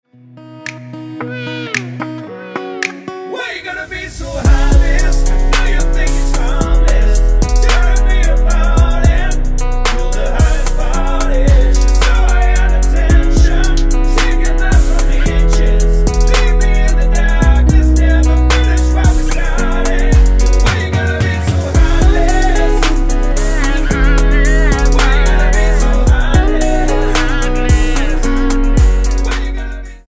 Tonart:B mit Chor
Die besten Playbacks Instrumentals und Karaoke Versionen .